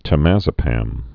(tə-măzə-păm)